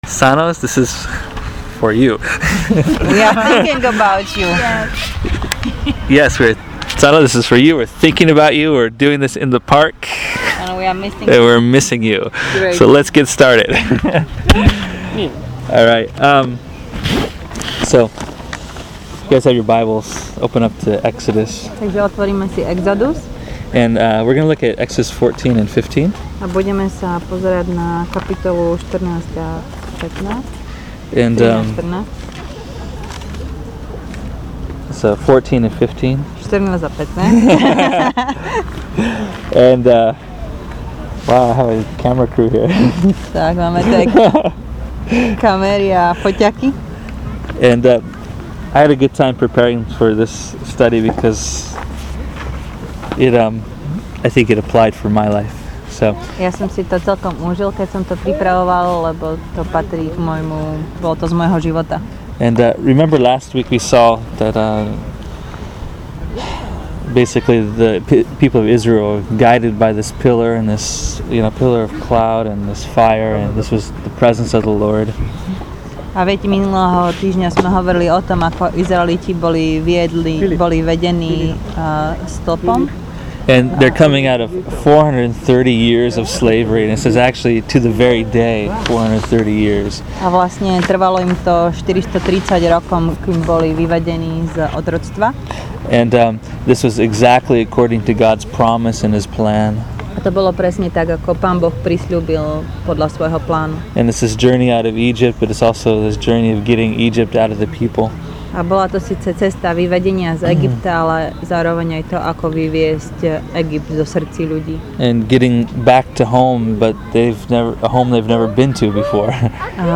So, let’s look at Exodus 14-15 to find out how to live it! Enjoy this teaching – “My Strong Song”